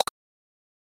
Uncymaze_pop.ogg